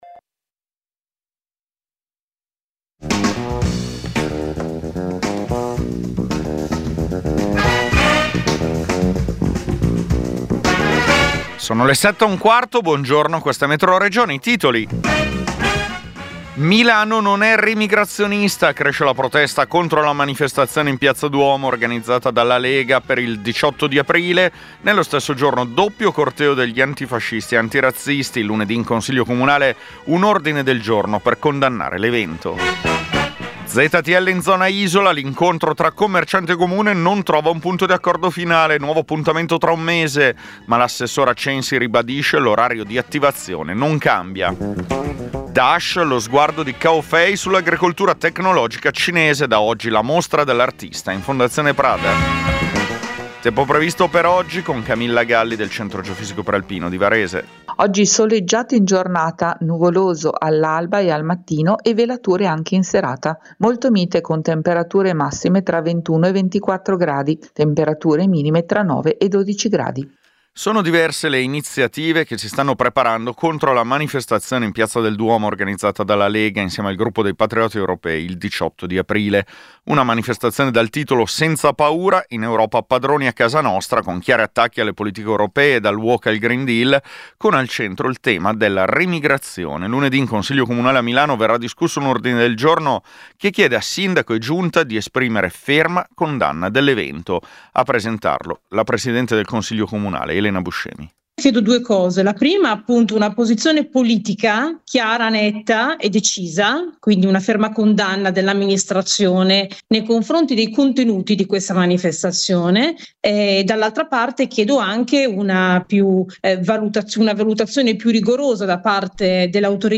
Metroregione è il notiziario regionale di Radio Popolare.